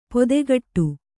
♪ podegaṭṭu